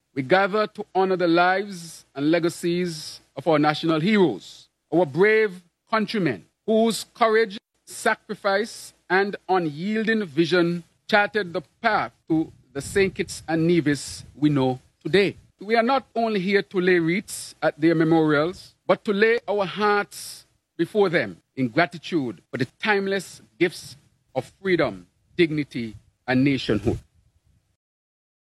Prime Minister, the Hon. Dr. Terrance Drew.
His comments were made at the annual National Heroes Day ceremony, on Tuesday Sep. 16th.